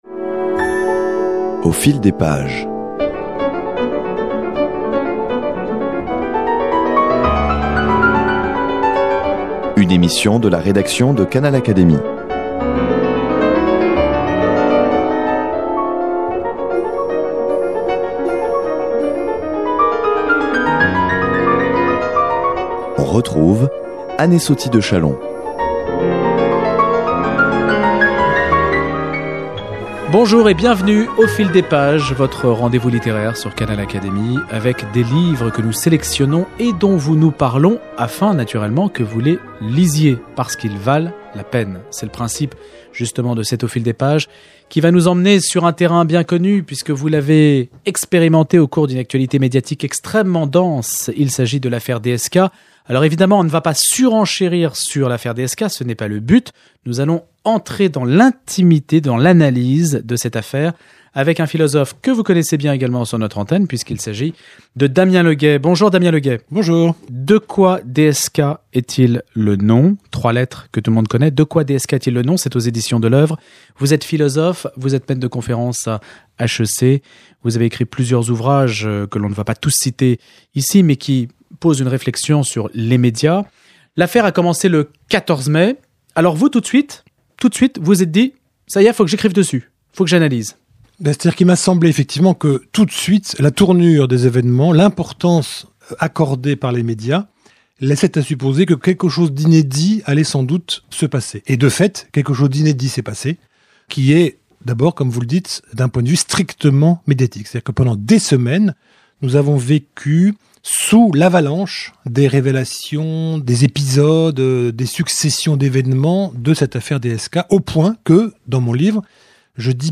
Dans l’entretien qu’il accorde ici à Canal Académie